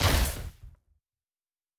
pgs/Assets/Audio/Sci-Fi Sounds/Weapons/Weapon 15 Shoot 2.wav at master
Weapon 15 Shoot 2.wav